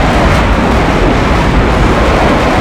veh_mig29_close_loop.wav